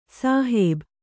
♪ sāhēb